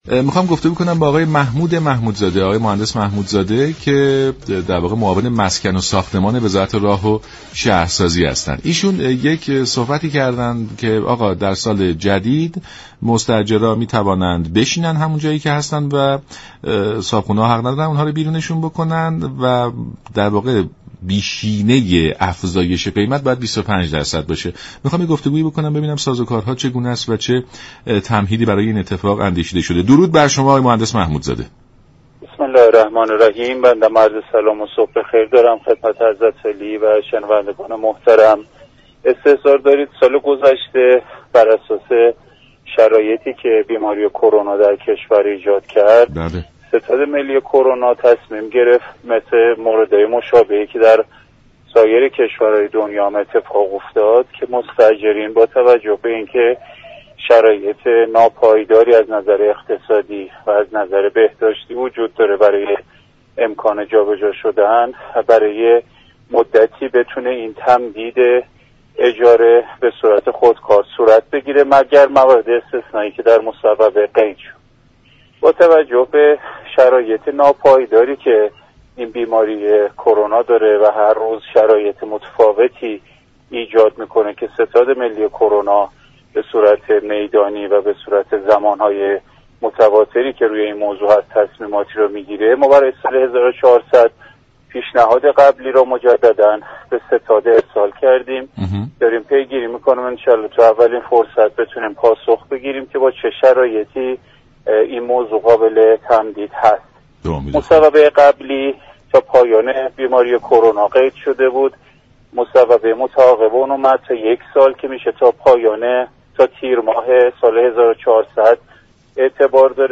به گزارش شبكه رادیویی ایران،«محمود محمودزاده» معاون مسكن و ساختمان وزارت راه و شهرسازی در برنامه «سلام صبح بخیر» رادیو ایران درباره نرخ اجاره بها در سال 1400 گفت: طبق اعلام وزارت راه و شهرسازی، مصوبه تیرماه سال گذشته ستاد ملی كرونا درخصوص تمدید قراردادها و میزان افزایش اجاره بها تا تیرماه امسال به قوت خود باقی است.